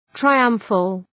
Προφορά
{traı’ʌmfəl}